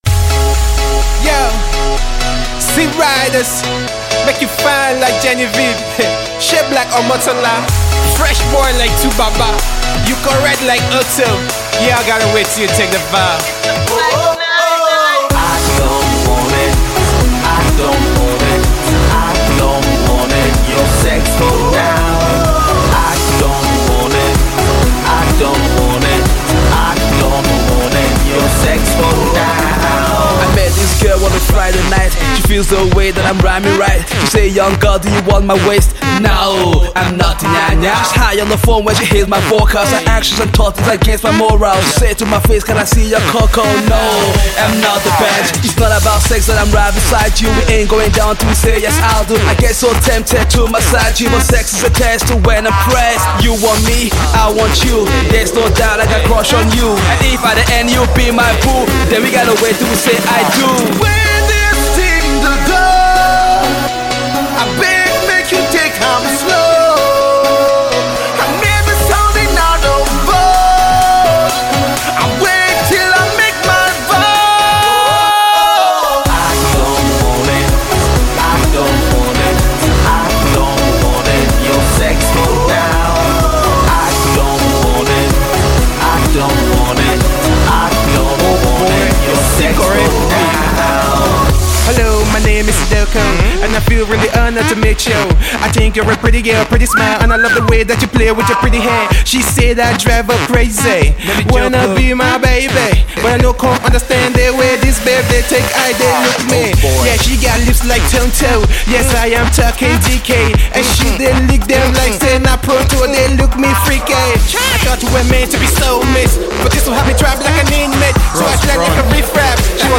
Gospel hip hop Duo
is a catchy techno single